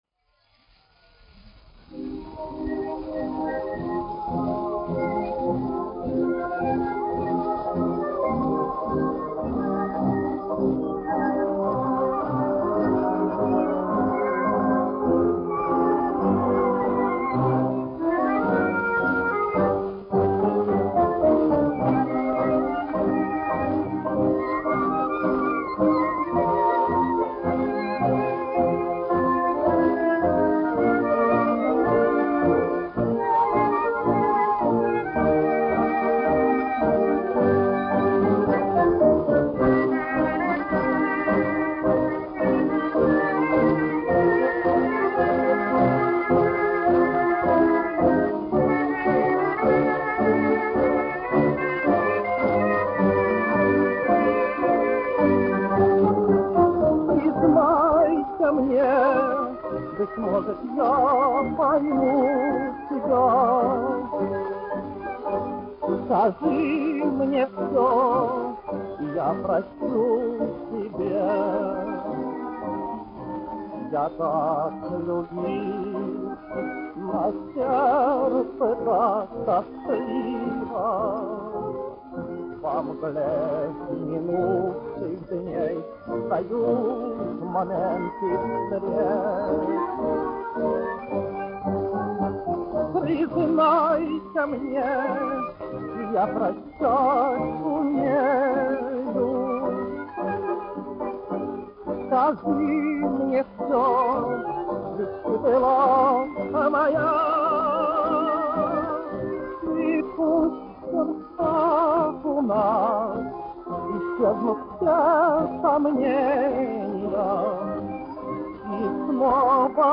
1 skpl. : analogs, 78 apgr/min, mono ; 25 cm
Populārā mūzika
Tango
Skaņuplate